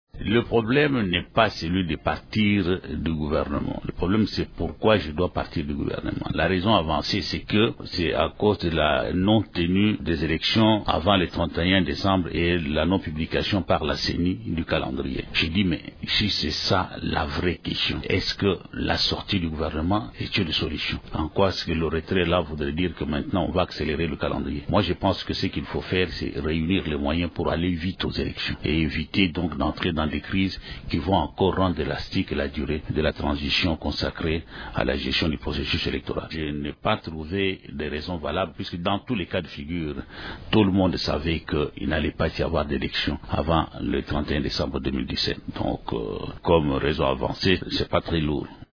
Lors d’un point de presse ce mardi, Pierre Kangudia  a déclaré s'être désolidarisé de la décision de l’UNC, estimant que les raisons avancées par ce parti ne sont pas valables :